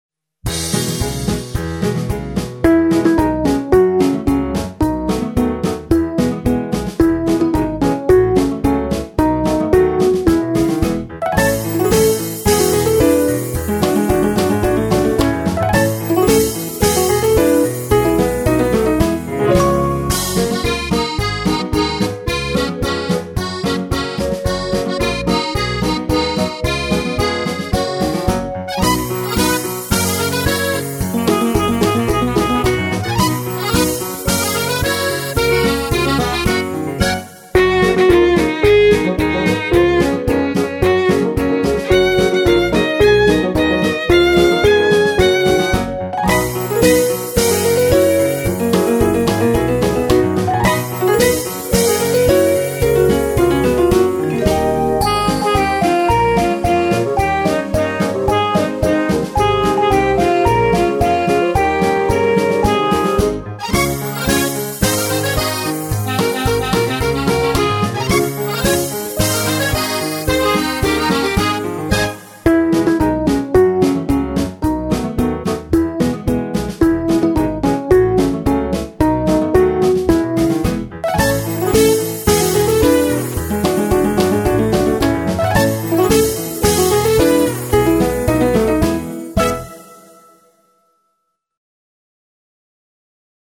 фонограмму (минус)